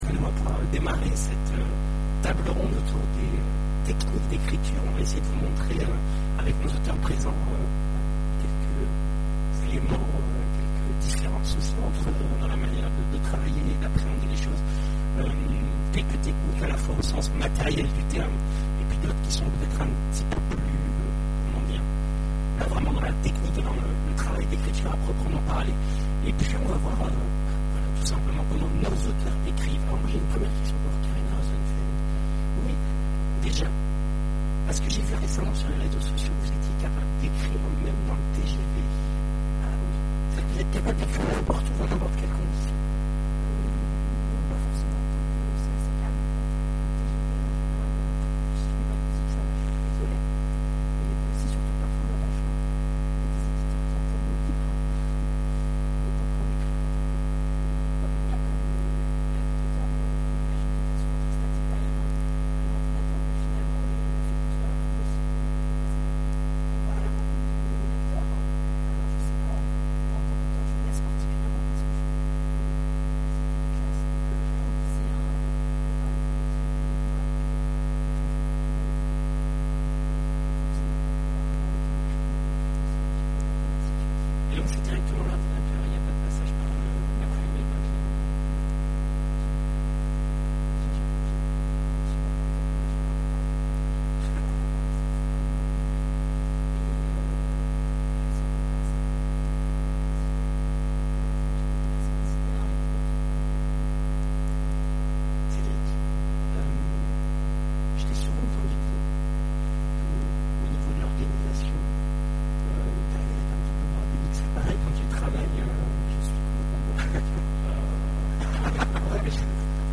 Imaginales 2014 : Conférence Mes techniques d'écriture
Conférence